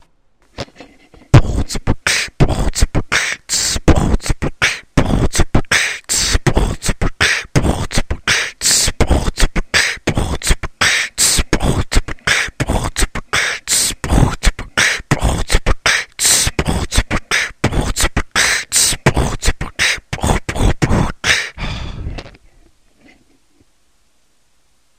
5)Bhh-t-b-kch-Bhh-t-b-kch-tss
Этот звук делается, как "Nasal Growl", только вместо буквы "m" надо произносить горловой "r".
Похож, но звук в моём бите глуше)